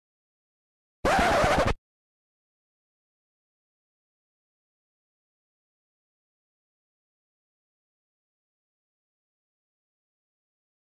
دانلود آهنگ پاشیدن خون 2 از افکت صوتی انسان و موجودات زنده
دانلود صدای پاشیدن خون 2 از ساعد نیوز با لینک مستقیم و کیفیت بالا
جلوه های صوتی